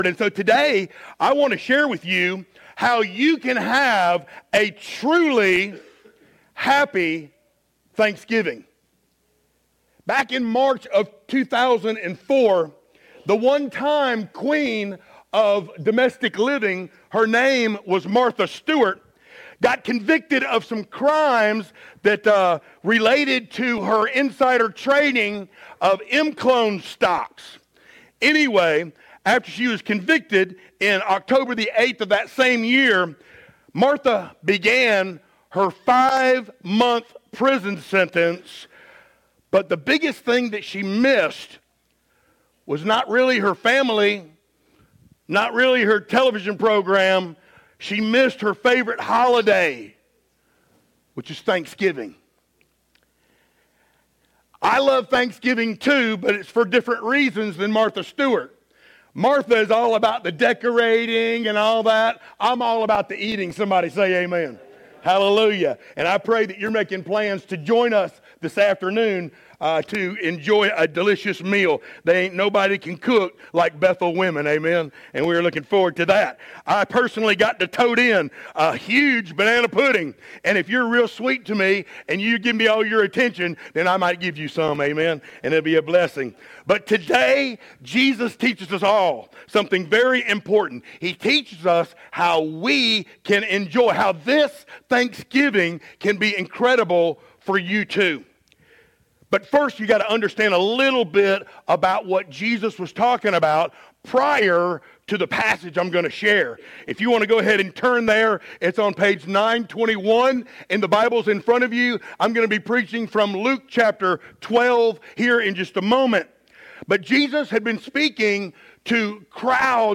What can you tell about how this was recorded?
Luke 12:13-34 Service Type: Sunday Morning Download Files Notes Topics